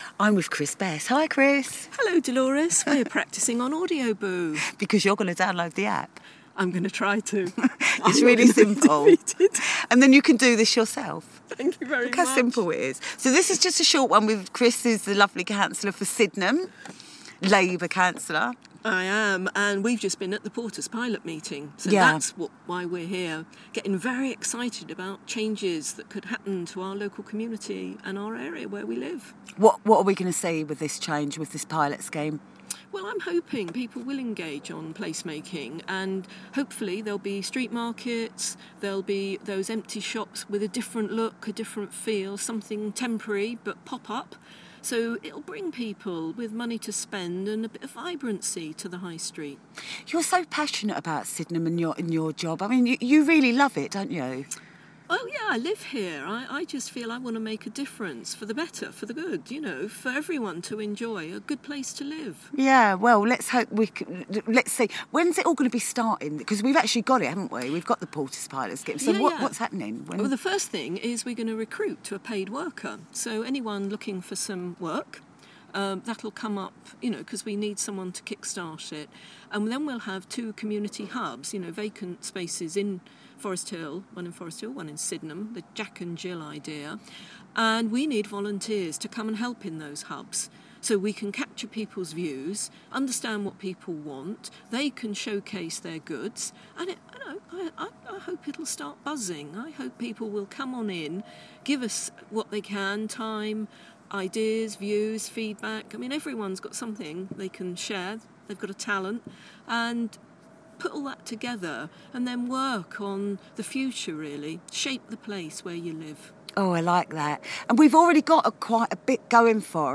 I speak to Councillor Christ Best about the Portas Pilot scheme coming to Sydenham and Forest Hill